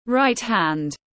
Tay phải tiếng anh gọi là right hand, phiên âm tiếng anh đọc là /raɪt hænd/.
Right hand /raɪt hænd/